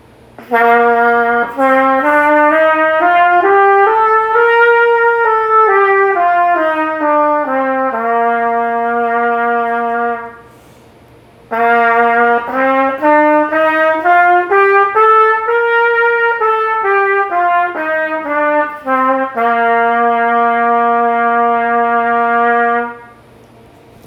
音のつなぎ目が美しくない
要は音と音の間に不自然な段差ができていたり、正確に音が出ていなかったりすることです。
【スケール悪い例】